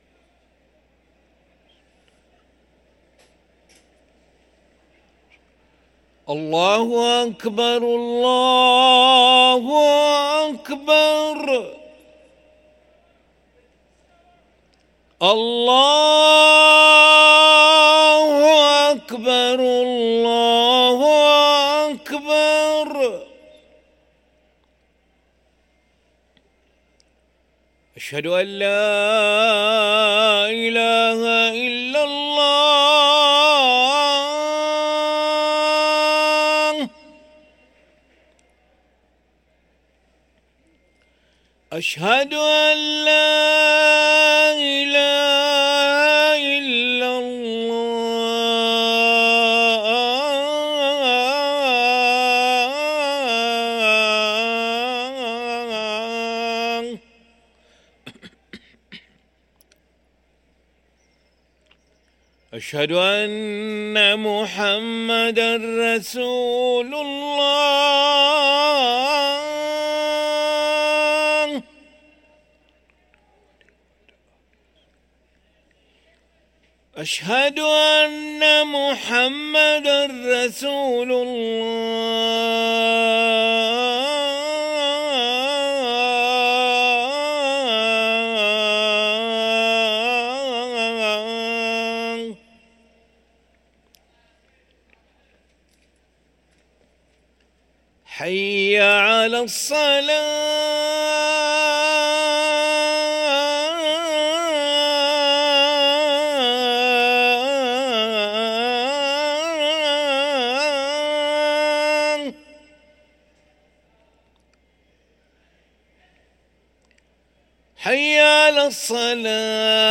أذان العشاء للمؤذن علي ملا الأحد 26 جمادى الأولى 1445هـ > ١٤٤٥ 🕋 > ركن الأذان 🕋 > المزيد - تلاوات الحرمين